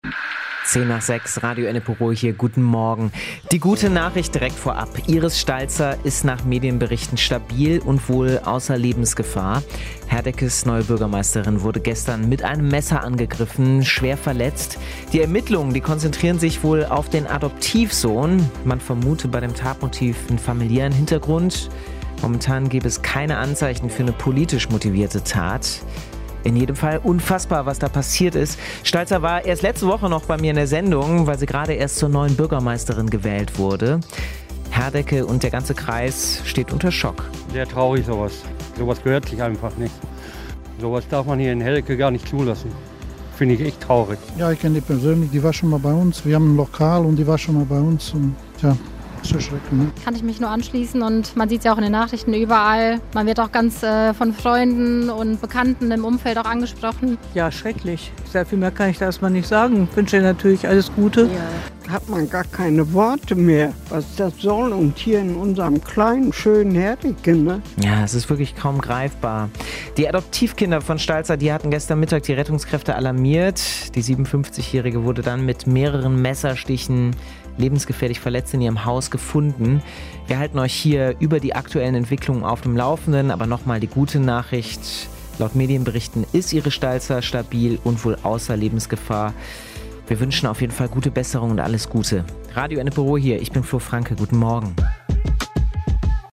Unsere Reporterin war in Herdecke unterwegs und hat mit euch gesprochen: Was sagt ihr zum Messerangriff auf Herdeckes neue Bürgermeisterin Iris Stalzer. Die Anteilnahme und Betroffenheit in Herdecke ist groß.